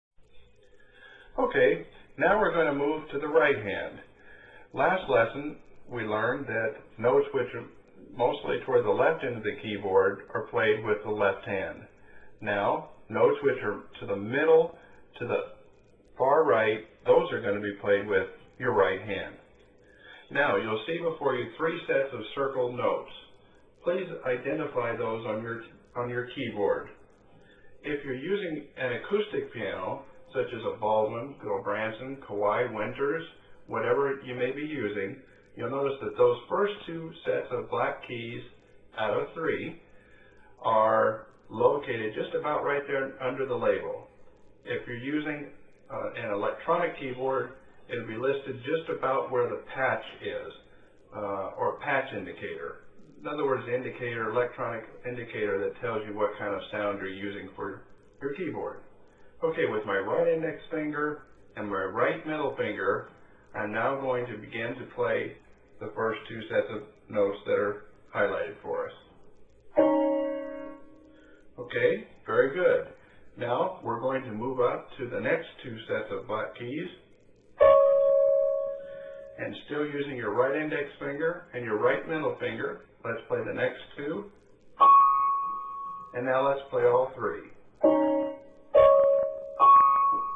there is a piano lesson audio instruction file to be played